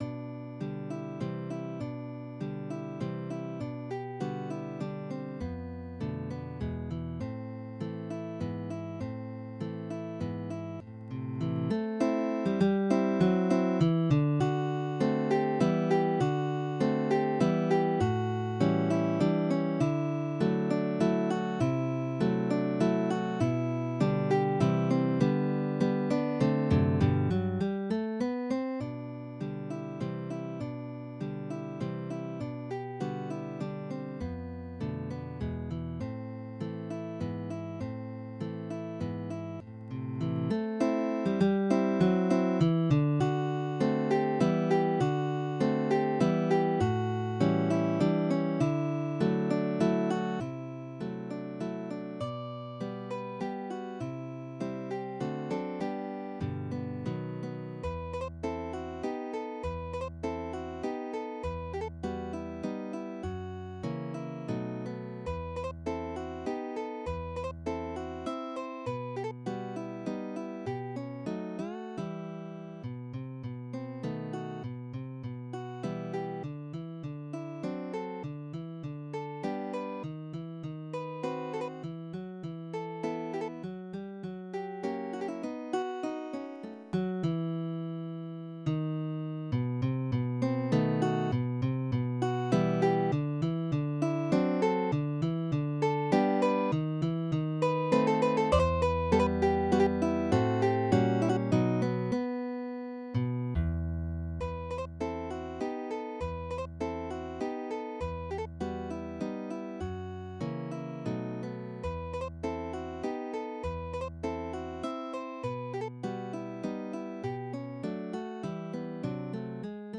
マズルカ
Midi音楽が聴けます 2 160円